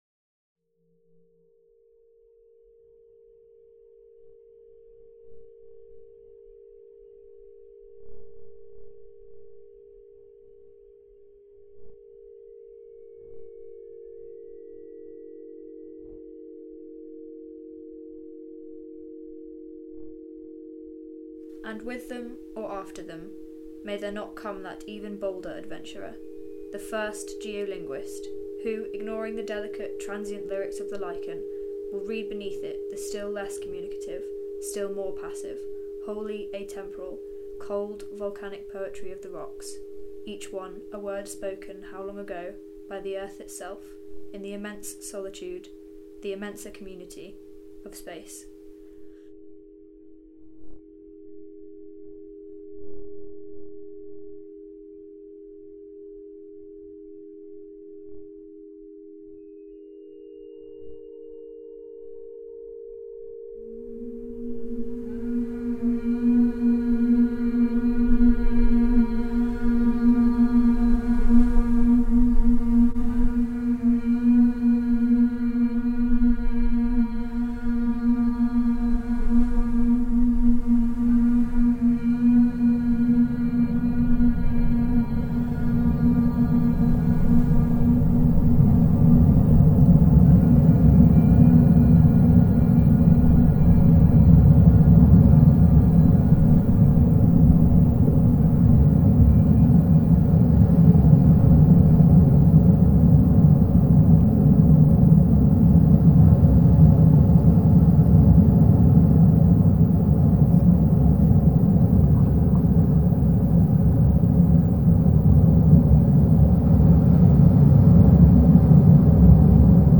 [please listen with headphones]
incantation-for-the-rocks.mp3